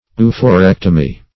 Oophorectomy \O`o*pho*rec"to*my\, n.
oophorectomy.mp3